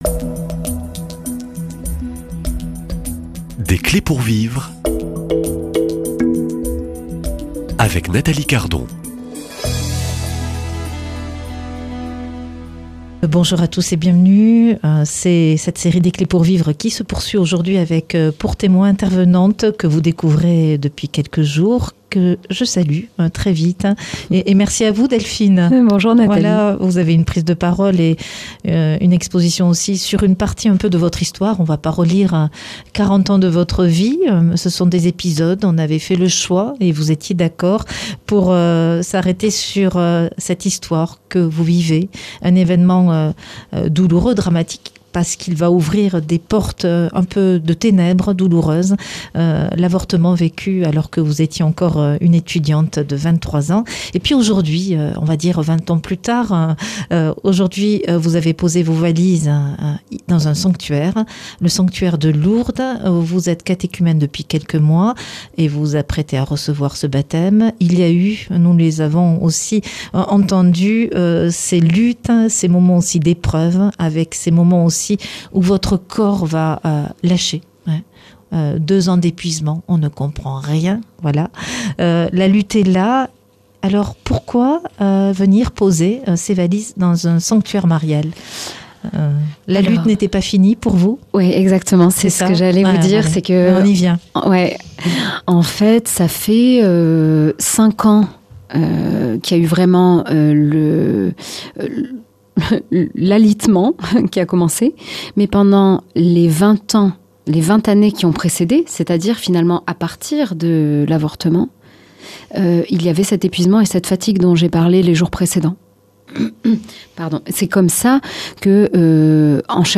Un témoignage poignant de transformation, de guérison et de résilience.